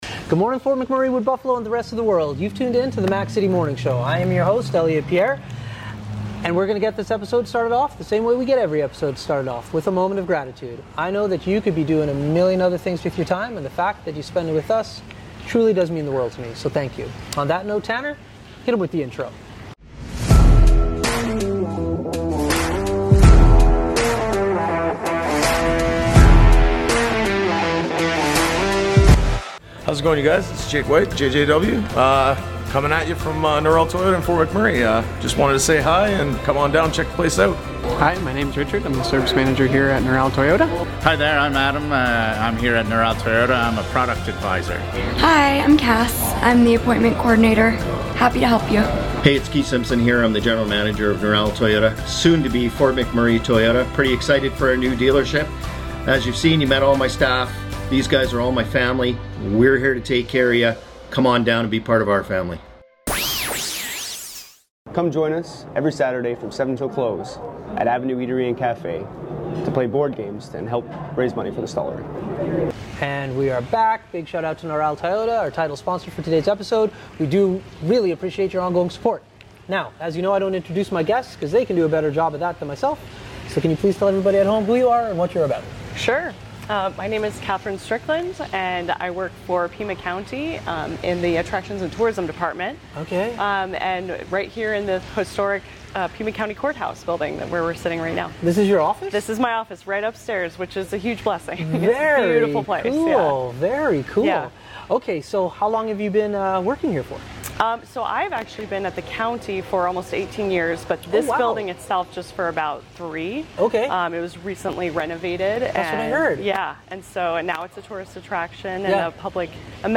On location